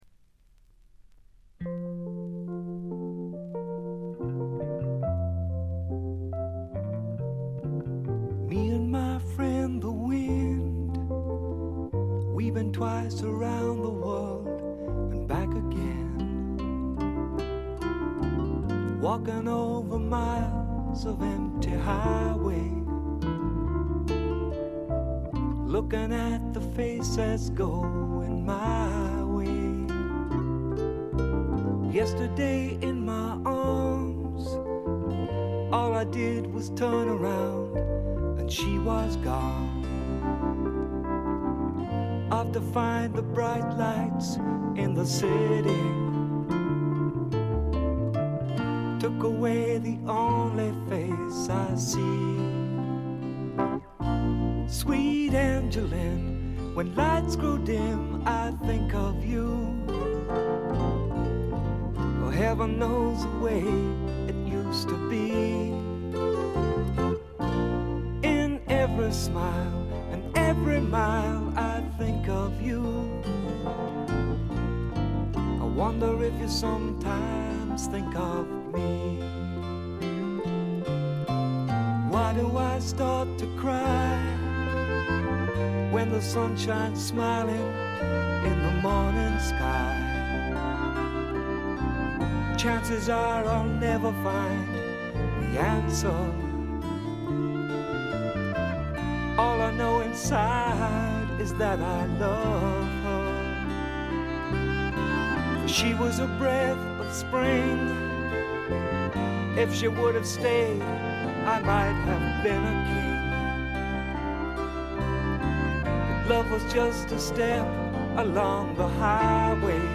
ほとんどノイズ感なし。
ピアノ系AOR系シンガー・ソングライターがお好きな方に大推薦です！
試聴曲は現品からの取り込み音源です。
Guitar